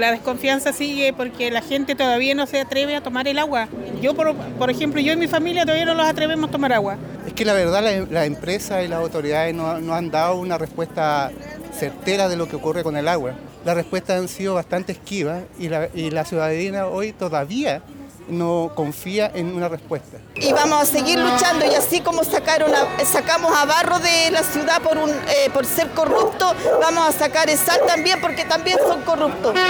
“Fuera Essal”fue lo que se escuchó durante todo el recorrido de la protesta que recorrió en sector céntrico de la ciudad.
Niños, adultos mayores, mujeres y hombres participaron de esta protesta, quienes expresaron su descontento y desconfianza en contra de la sanitaria.